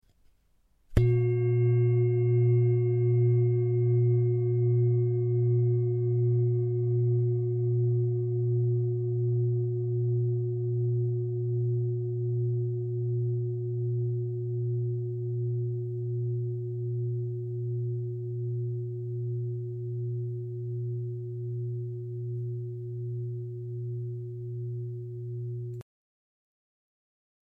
Indische Bengalen Klangschale - CERES
Gewicht: 2049 g
Grundton: 118,55 Hz
1. Oberton: 360,68 Hz
PLANETENTON CERES TON AIS